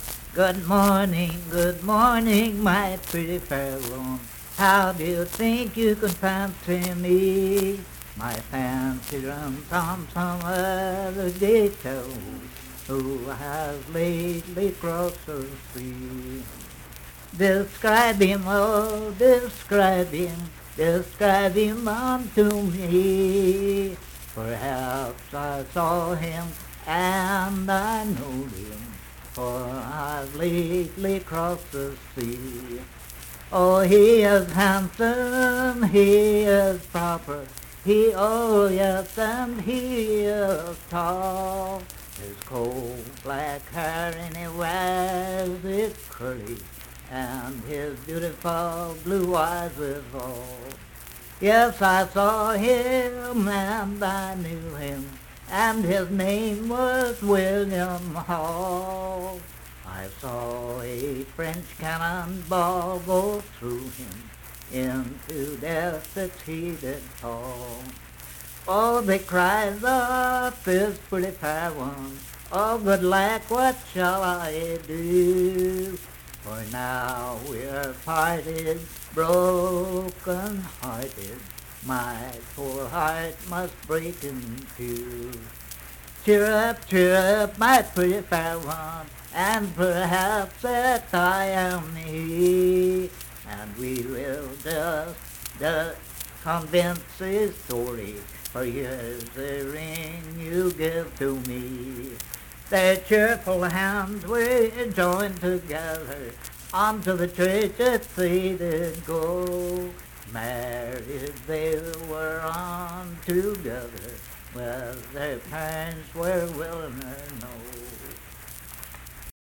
Unaccompanied vocal music
Voice (sung)
Huntington (W. Va.), Cabell County (W. Va.)